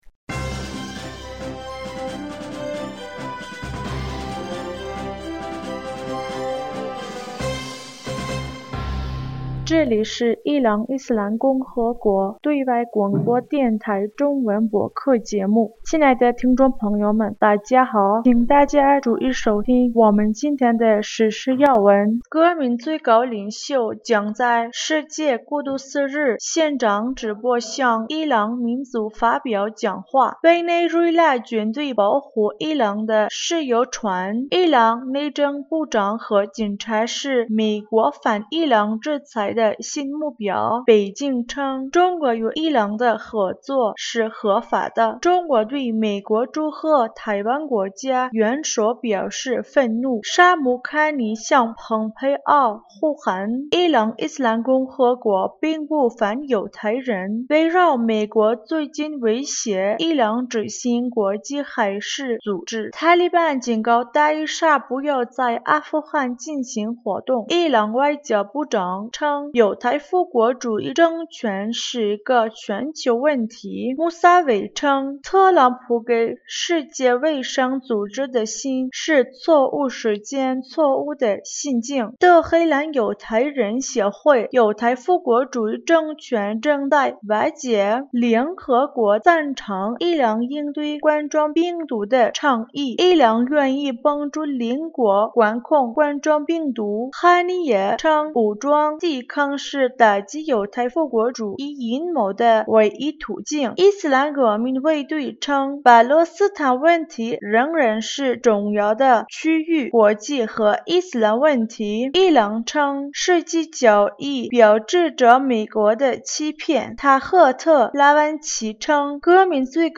2020年5月21日 新闻